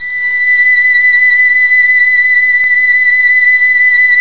Index of /90_sSampleCDs/Roland - String Master Series/STR_Violin 2&3vb/STR_Vln2 % + dyn